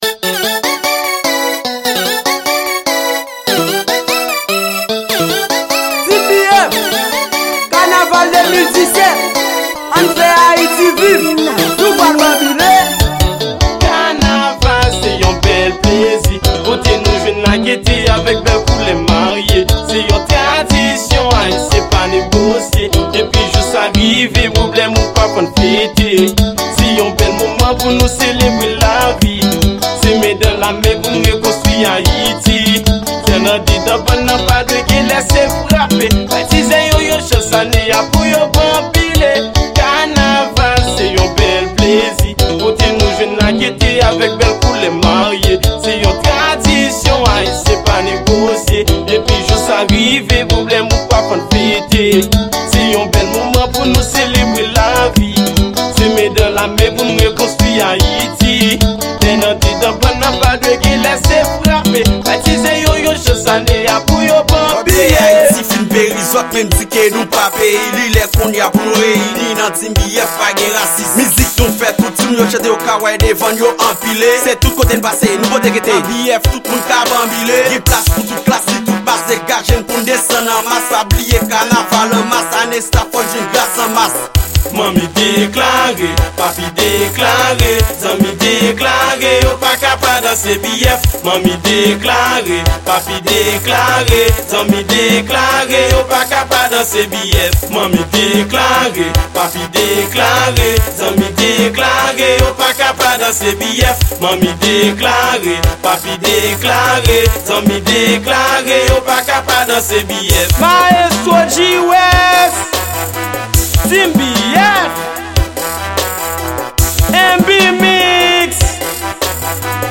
Genre: Kanaval.